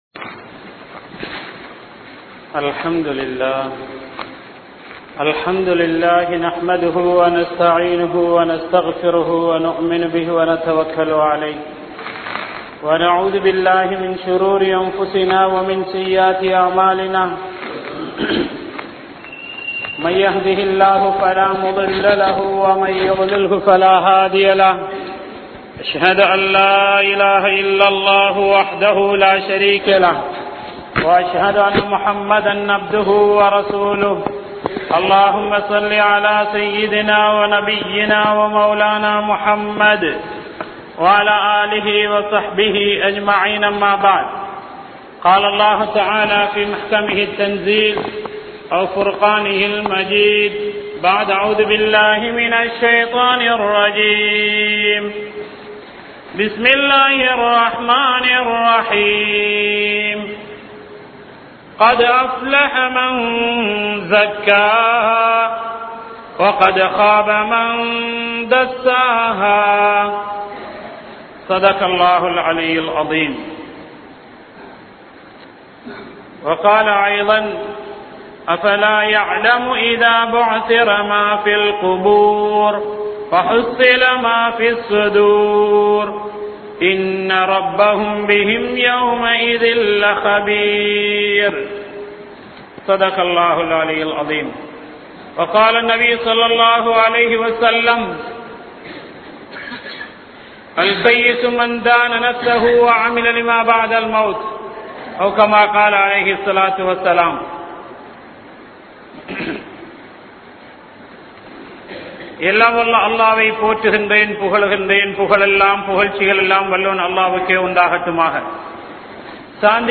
Ullaththai Thooimaiyaakkuvoam (உள்ளத்தை தூய்மையாக்குவோம்) | Audio Bayans | All Ceylon Muslim Youth Community | Addalaichenai